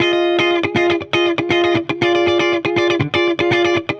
Index of /musicradar/dusty-funk-samples/Guitar/120bpm
DF_70sStrat_120-F.wav